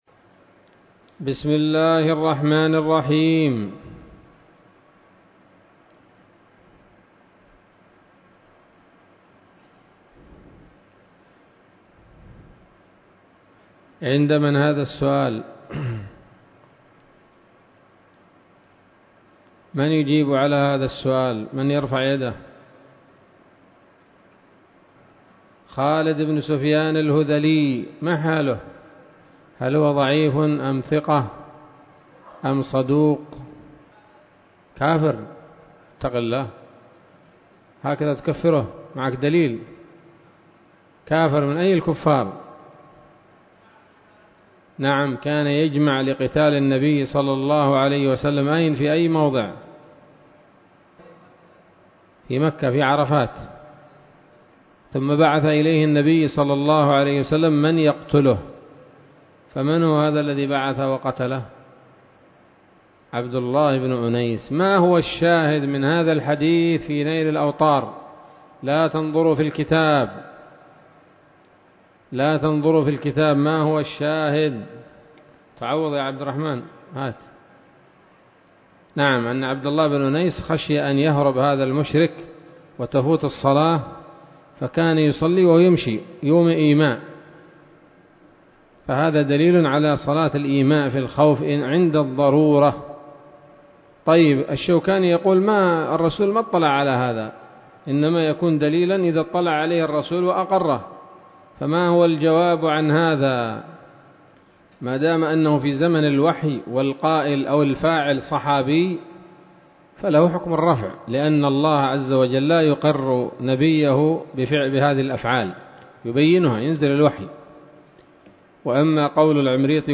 الدرس السابع وهو الأخير من ‌‌‌‌كتاب صلاة الخوف من نيل الأوطار